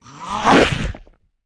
Index of /App/sound/monster/misterious_diseased_spear
attack_act_1.wav